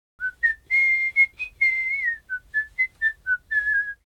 Звуки свиста, свистков
Человек насвистывает мелодию ртом